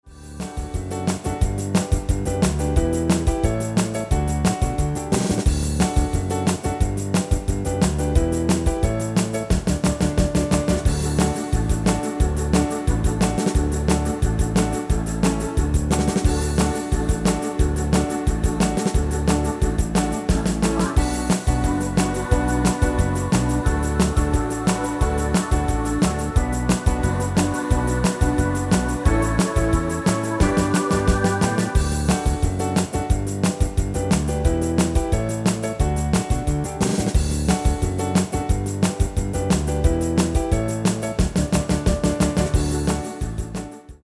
Demo/Koop midifile
Genre: Evergreens & oldies
Toonsoort: D
- Vocal harmony tracks